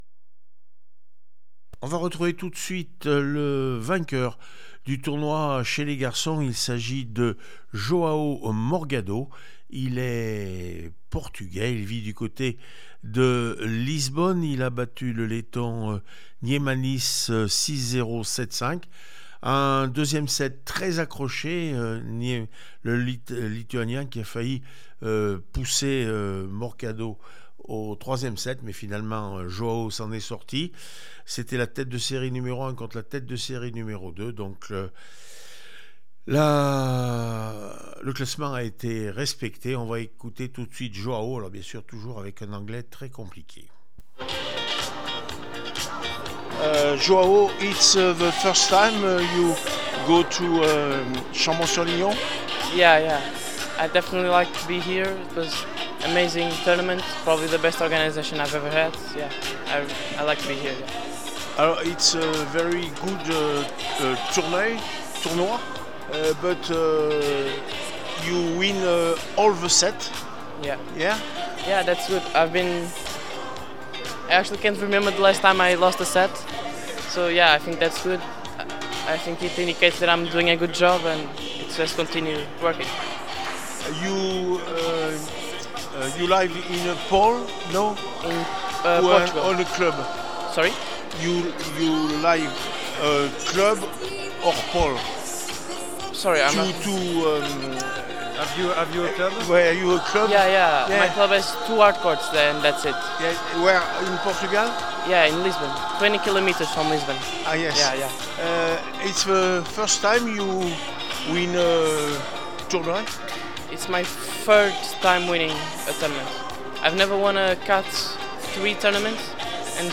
Tournoi international de tennis du Chambon sur Lignon 2023 les réactions